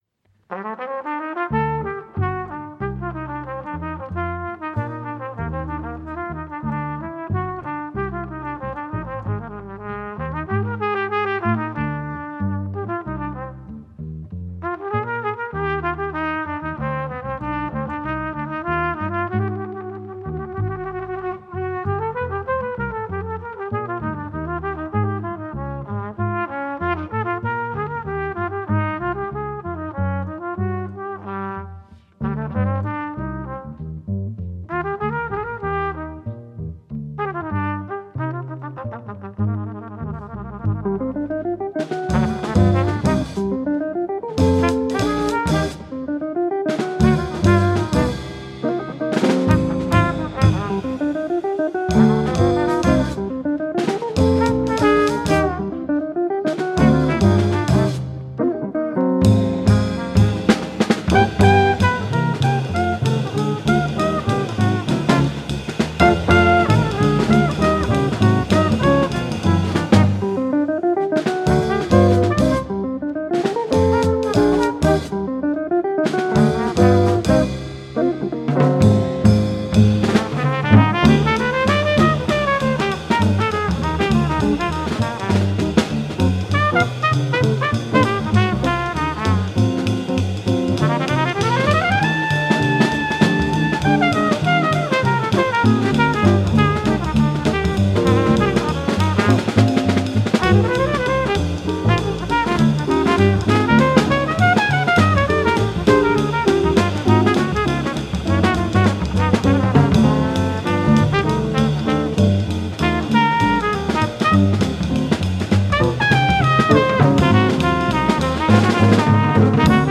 Trumpet
Guitar
Contra Bass
Drums.